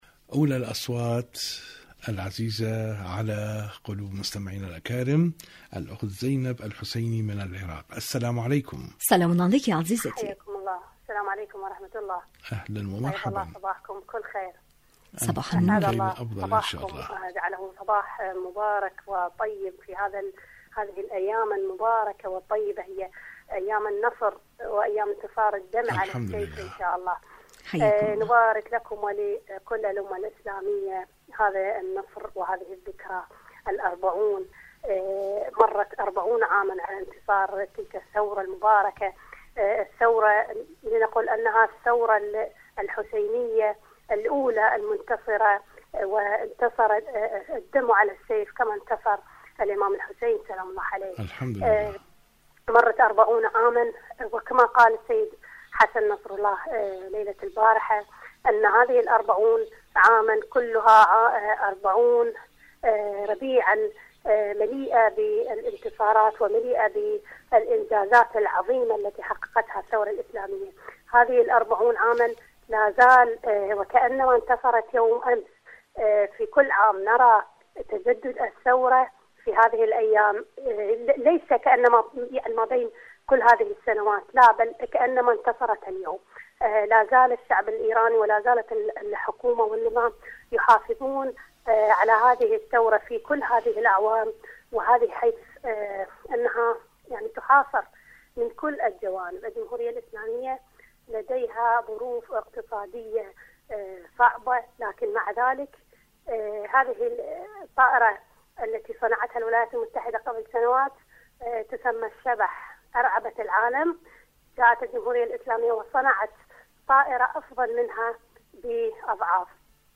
برنامج : المنتدى الإذاعي / مشاركة هاتفية